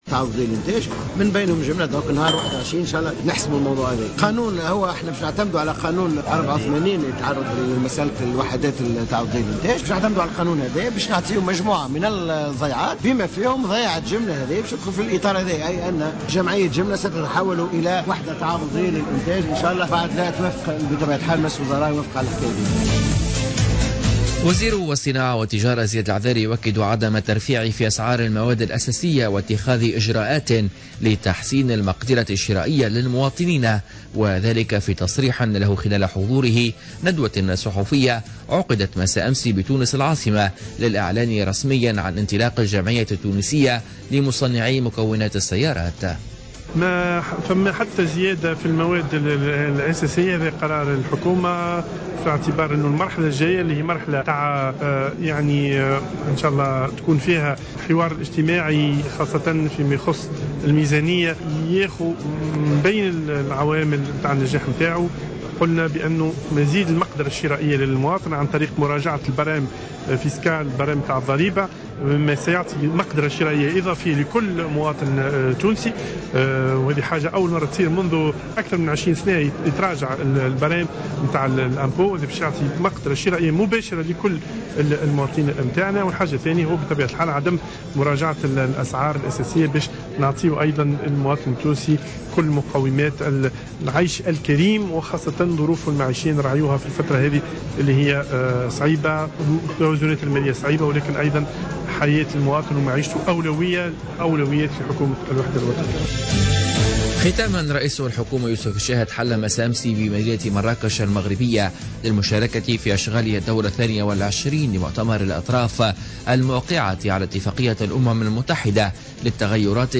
نشرة أخبار منتصف الليل ليوم الثلاثاء 15 نوفمبر 2016